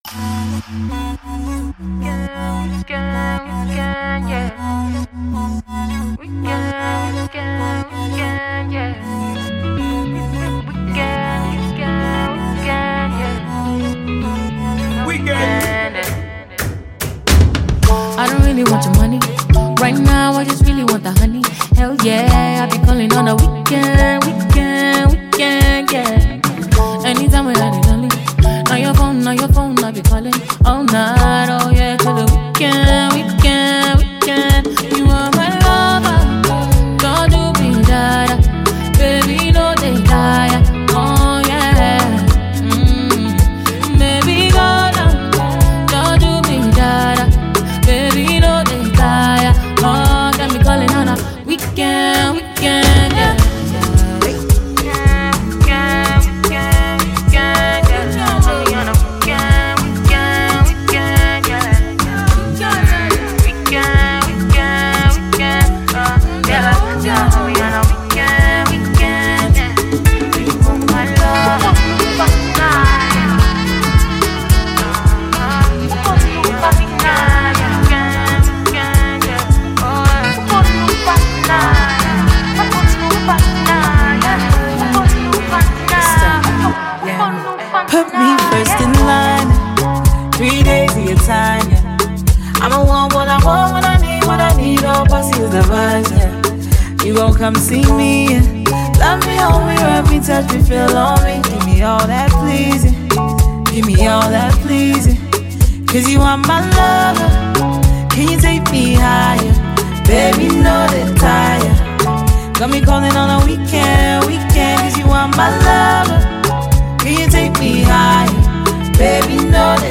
Nigerian Afropop singer and songwriter